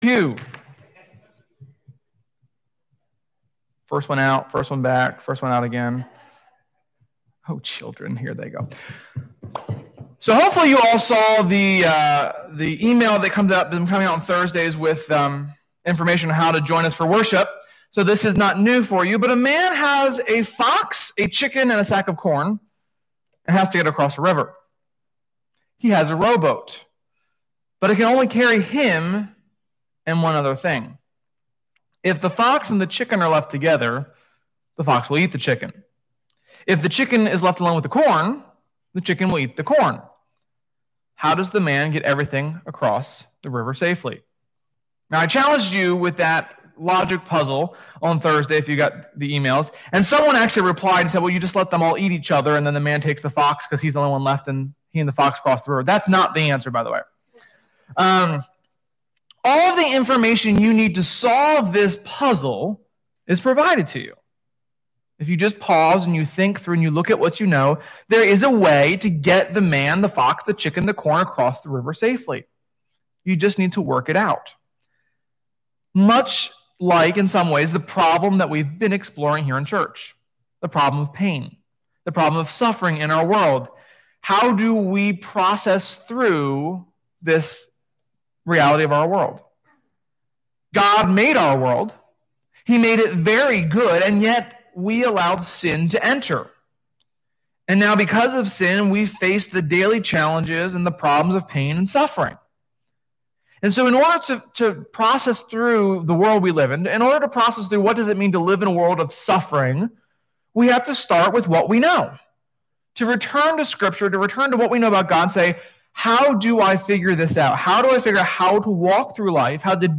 Sermon-8.9.20.mp3